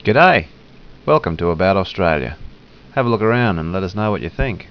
Hear him say a dinky-di Aussie G'day
Gday.wav